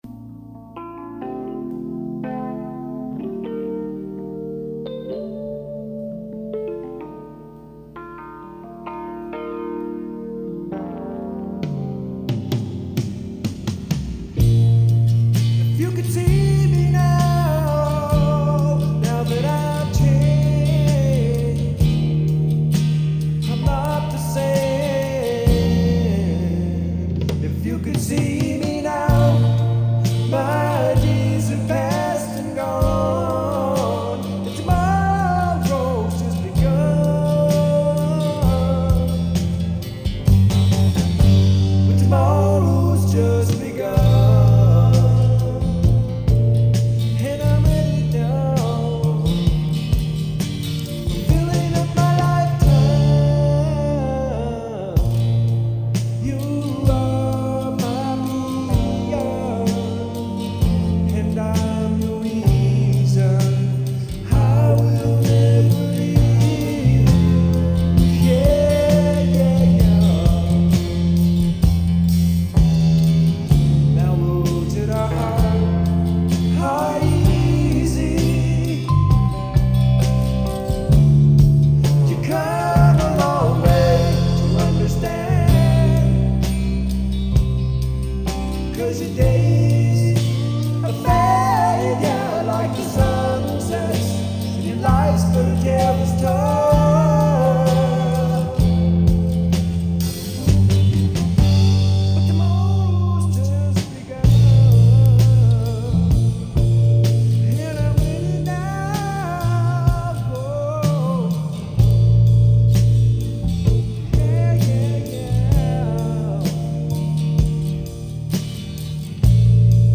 THIS IS A RAW TAKE WITHOUT MIX AND PROPER EDITING